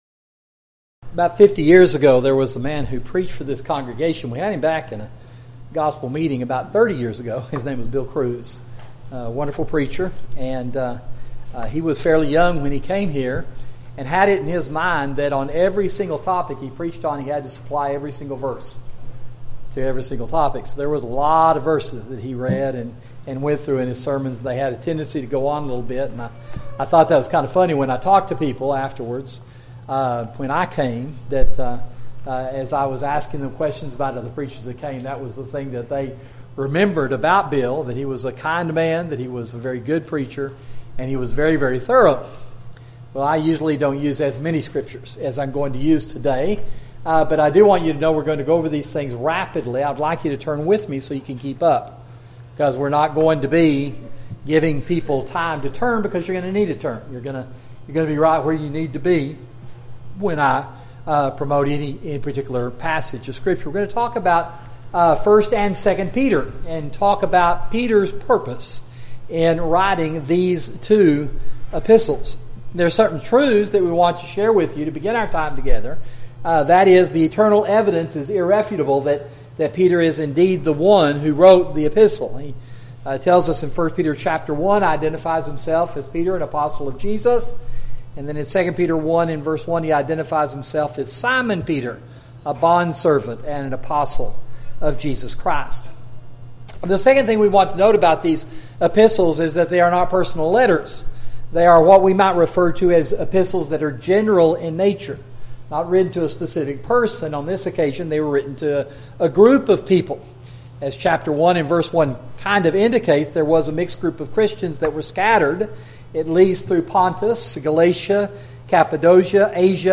Audio PPTX (Right Click to SAVE) YouTube Video of Sermon <<———><><———>> Share this: Tweet